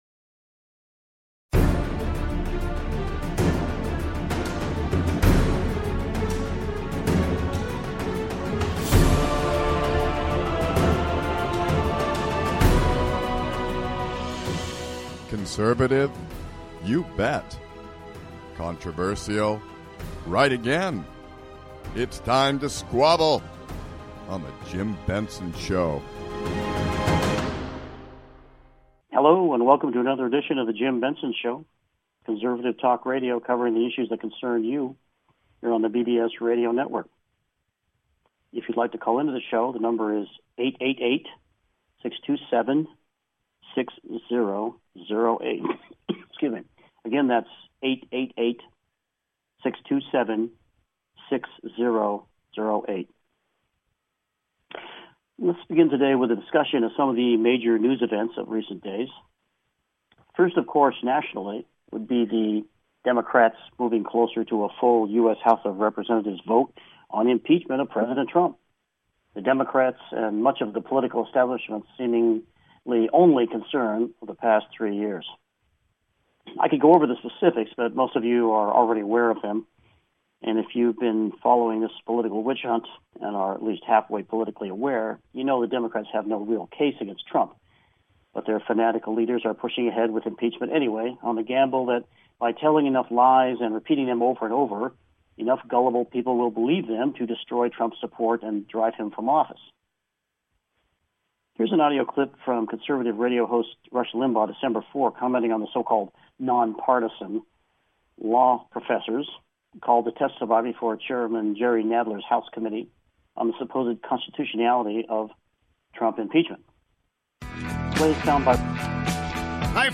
Talk Show Episode, Audio Podcast
conservative talk radio done right, addressing the issues that concern you.